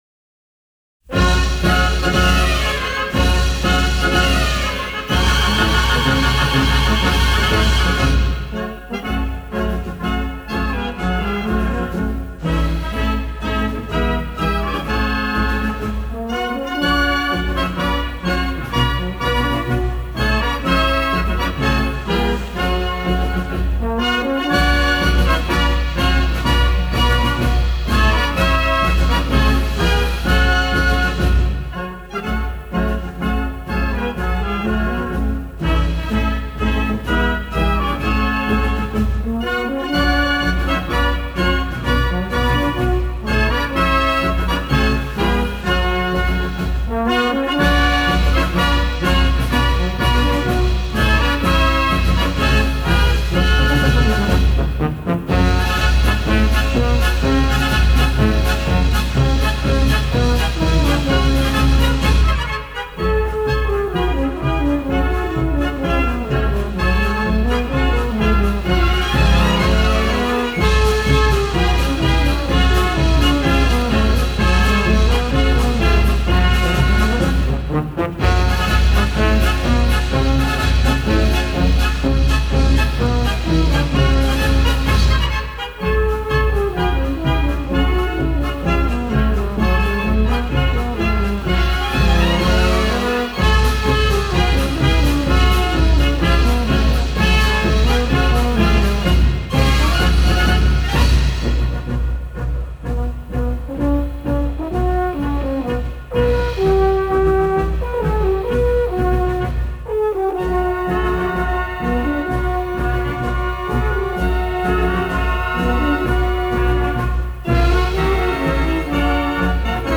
Марши